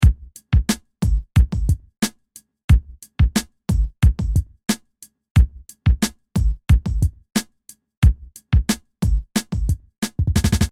描述：4条嘻哈节拍
标签： 180 bpm Hip Hop Loops Drum Loops 1.80 MB wav Key : Unknown
声道立体声